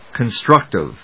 音節con・struc・tive 発音記号・読み方
/kənstrˈʌktɪv(米国英語)/